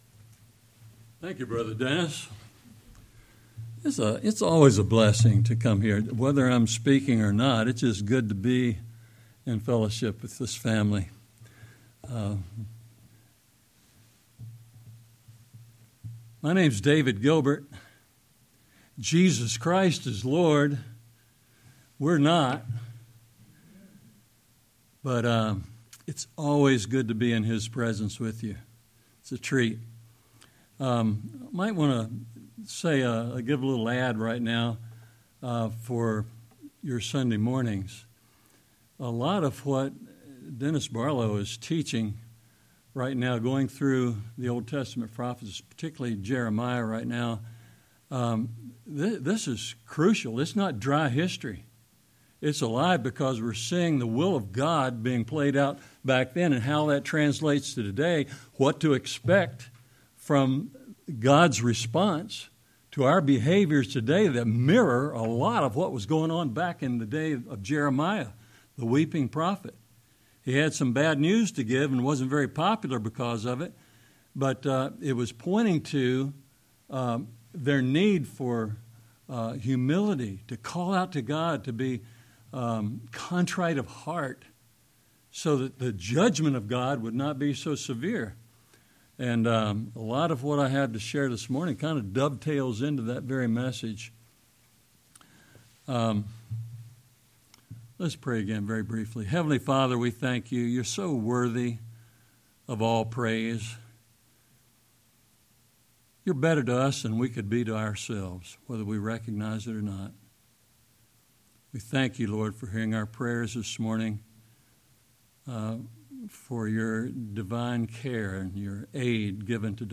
sermon10-5-25.mp3